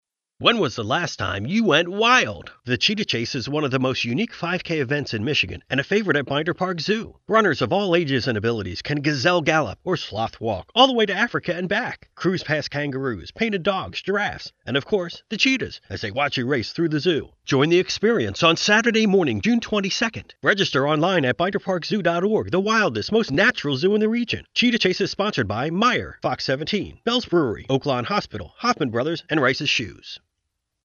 Male
English (North American)
Adult (30-50), Older Sound (50+)
Regional Television Commercial
1211TVR_Commercial_Binder_Park_Zoo.mp3